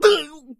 PigHit 04.wav